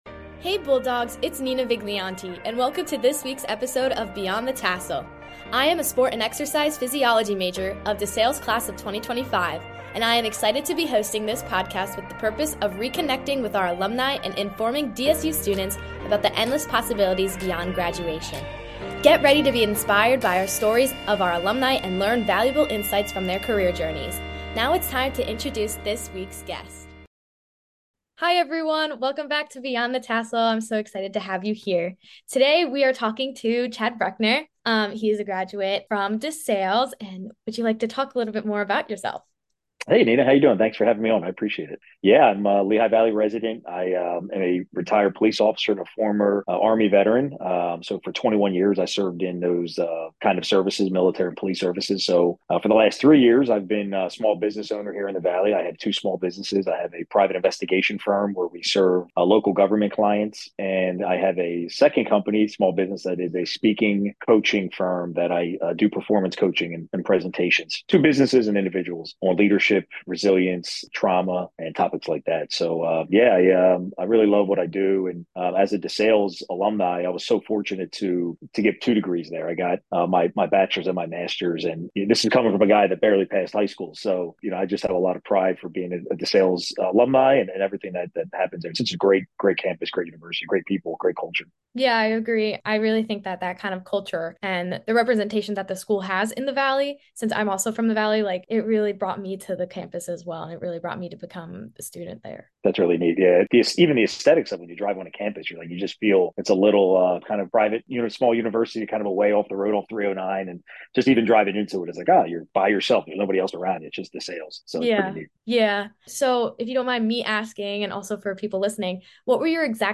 Play Rate Listened List Bookmark Get this podcast via API From The Podcast Beyond the Tassel reconnects with DeSales University alumni to inspire and inform current students about the infinite opportunities that our graduates have experienced beyond college. Through informational interviews, this podcast will share stories, experiences, insights and resources to help current DeSales University students explore real world opportunities and spark further career curiosity.